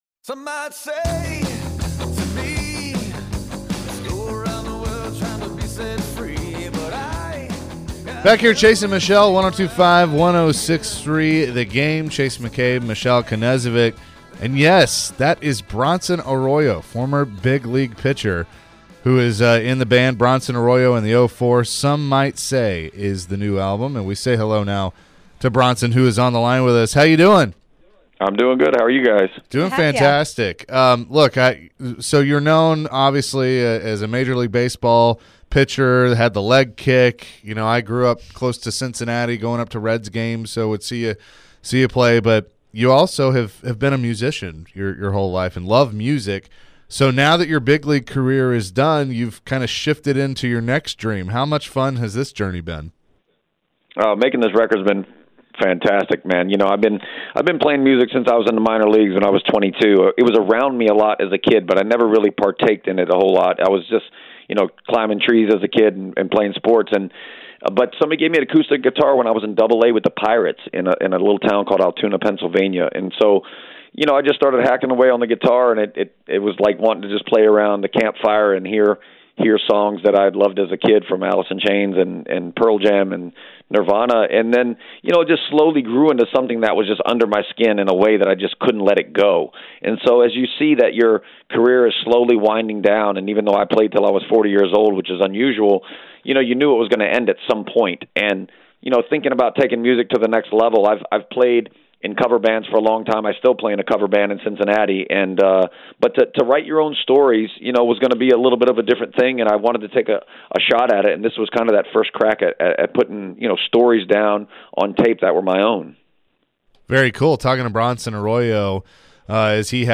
Bronson Arroyo Interview (2-22-23)